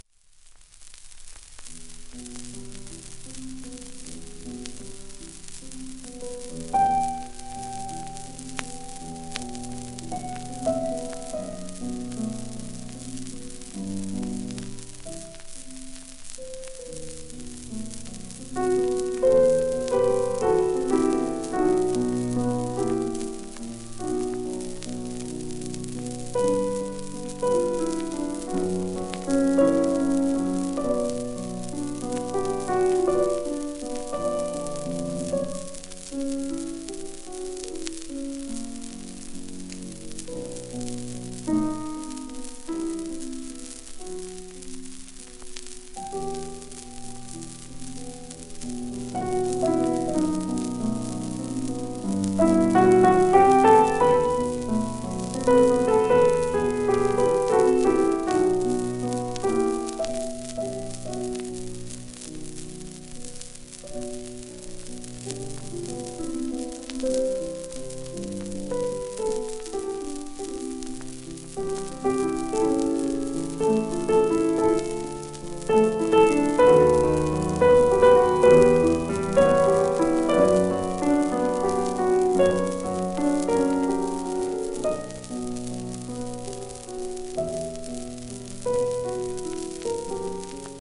1951年録音
ウクライナ系アメリカ人ピアニスト。
シェルマン アートワークスのSPレコード